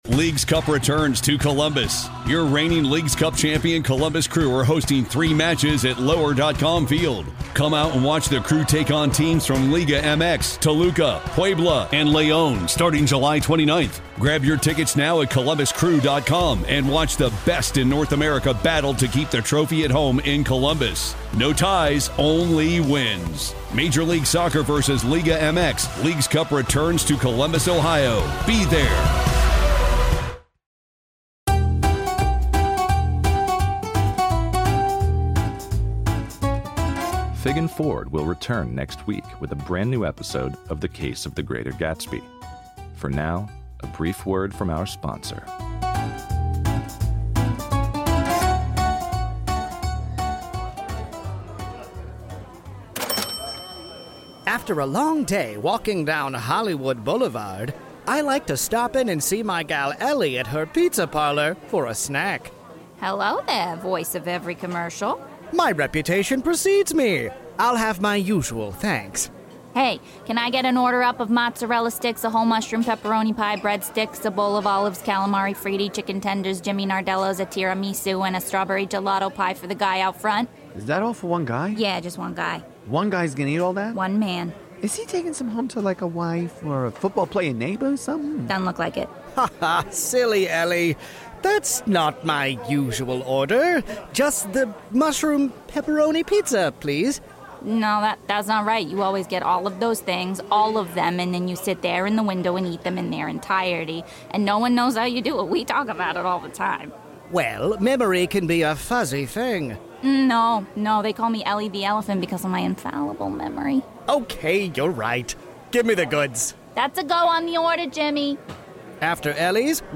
Fig & Ford will be back with a brand new episode next week, but in the meantime, here's a word from one of our sponsors.